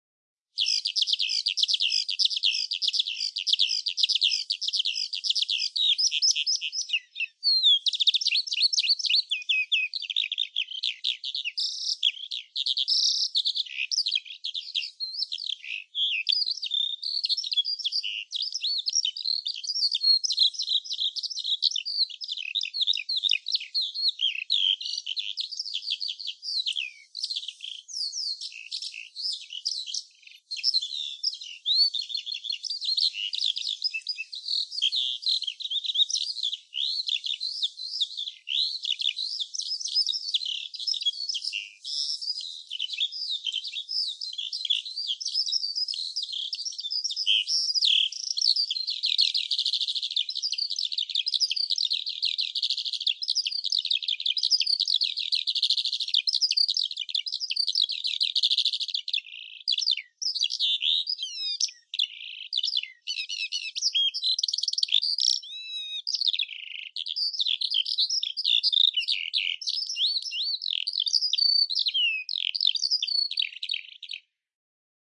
自然界的鸟鸣
描述：森林春天鸟鸣鸟
标签： 鸟鸣 春天 自然
声道立体声